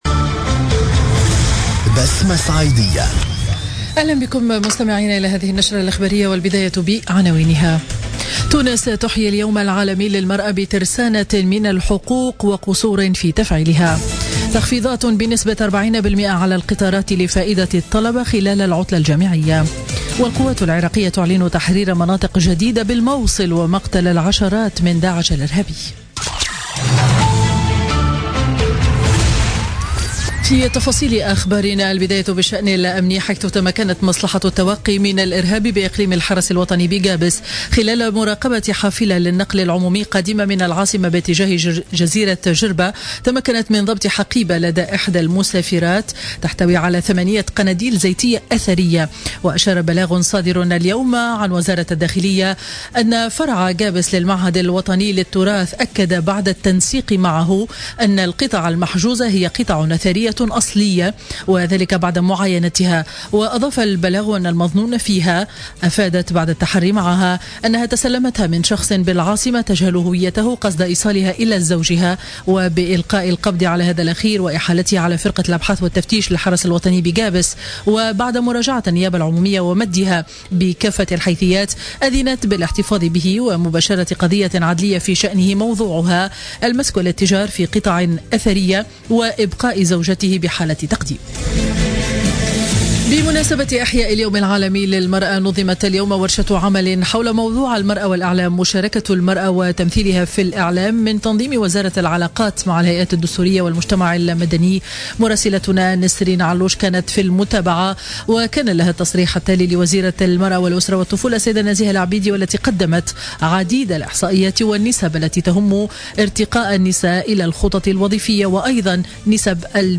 نشرة أخبار منتصف النهار ليوم الإربعاء 8 مارس 2017